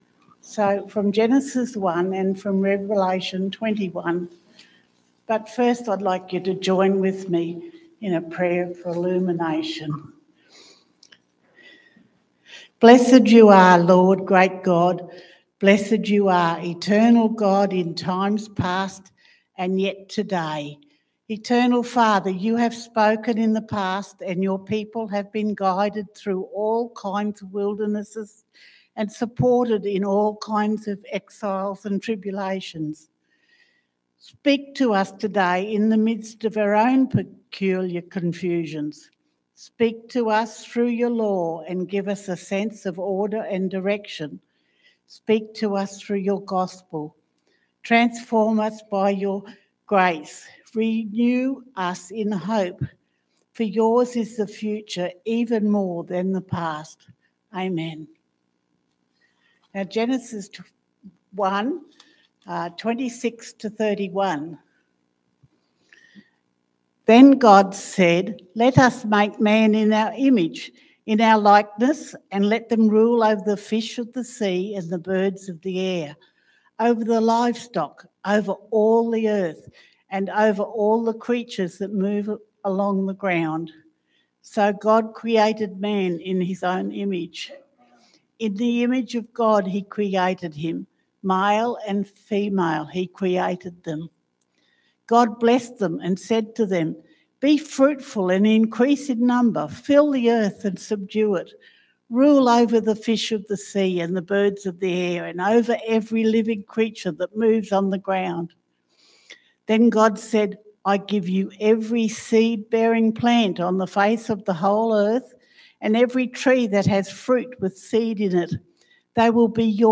Revelation 21:1-5 Service Type: Sunday Morning « Totality